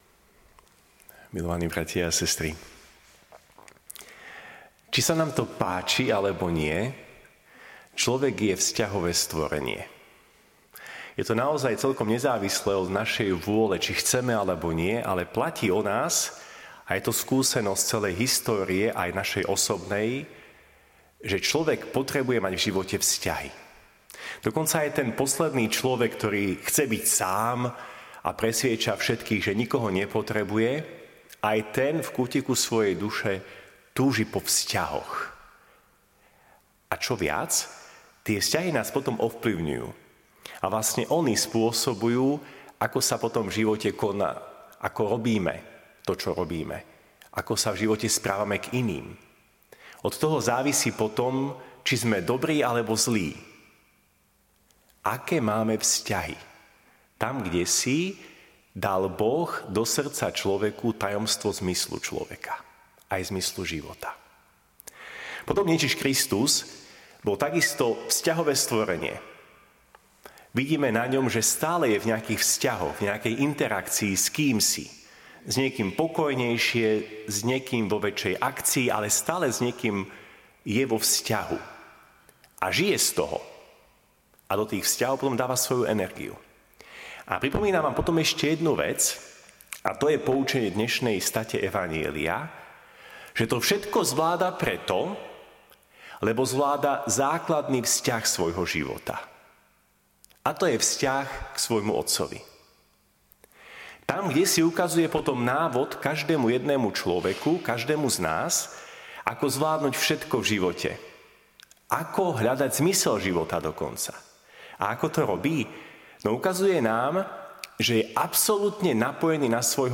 Streda po 4. pôstnej nedeli
Podcasty Kázne ČLOVEK POTREBUJE VZŤAHY